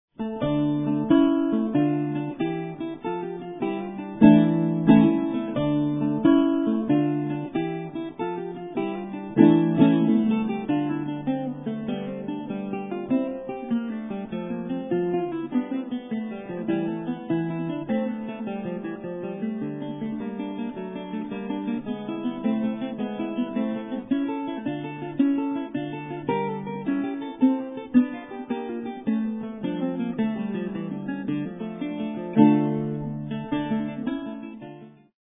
he plays the seldom heard Baroque guitar